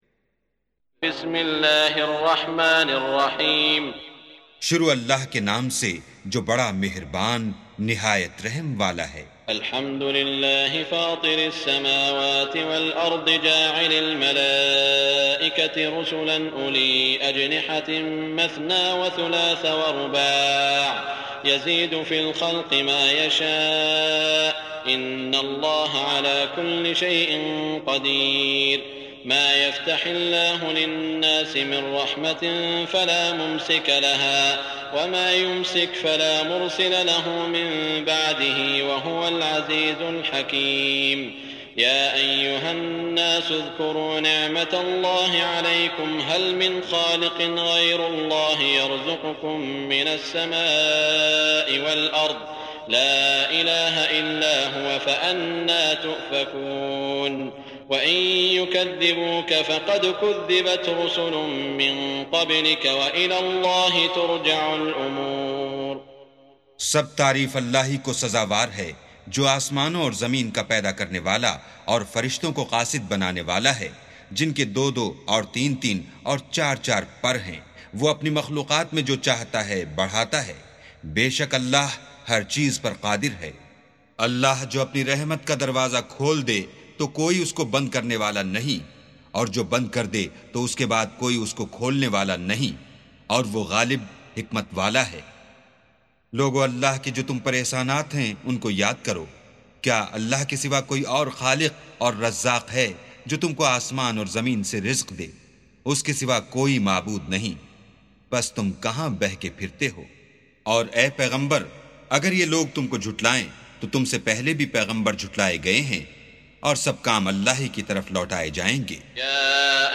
سُورَةُ فَاطِرٍ بصوت الشيخ السديس والشريم مترجم إلى الاردو